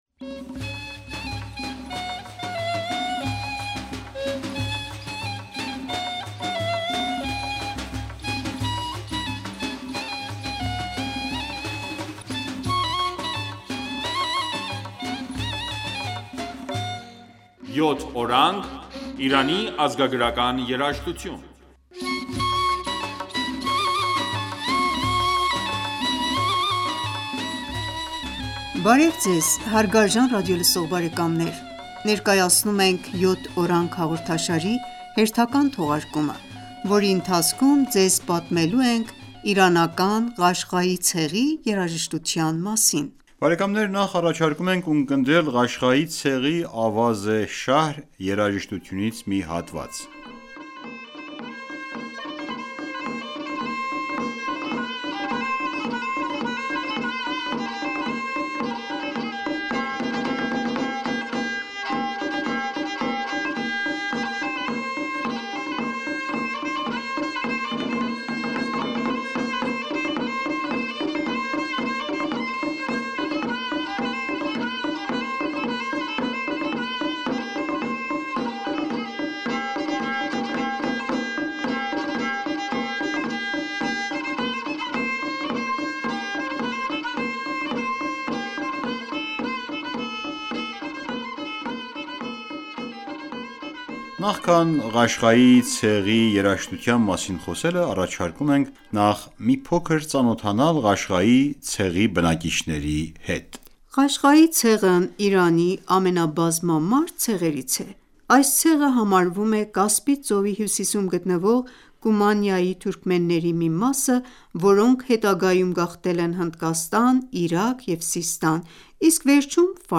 Բարև Ձեզ հարգարժան ռադիոլսող բարեկամներ: Ներկայացնում ենք «Յոթ օրանգ» հաղորդաշարի հերթական թողարկումը,որի ընթացքում ձեզ պատմելու ենք Իրանական Ղաշղայի ցեղի երաժշտության մասին:
Նախ առաջարկում ենք ունկնդրել Ղաշղայի ցեղի «Ավազ-ե շահր» երաժշտությունից մի հատված: